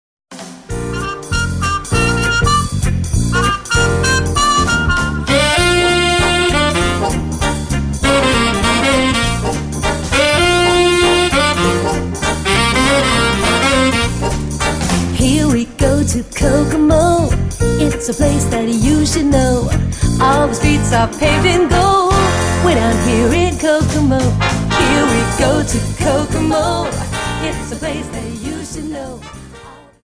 Dance: Quickstep Song